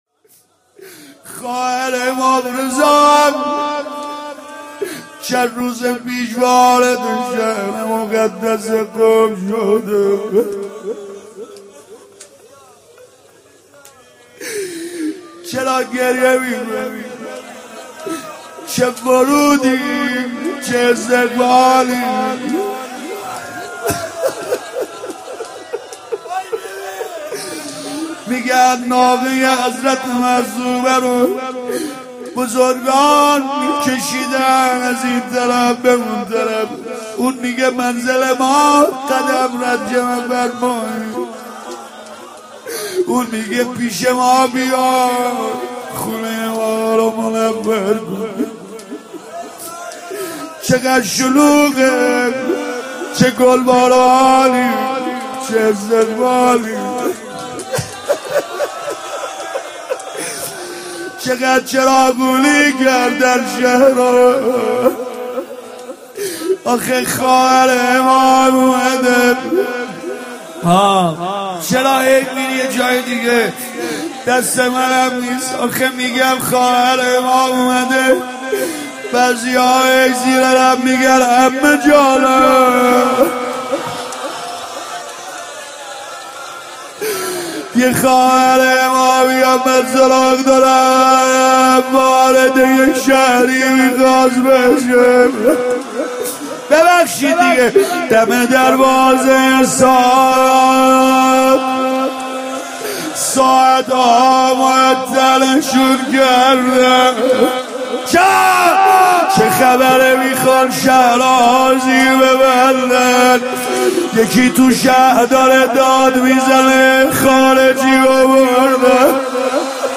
دانلود مداحی جدید سید رضا نریمانی – جلسه هفتگی ۲۳ آذر ۹۶
امون ز شام ختم کلام (روضه حضرت زینب (س)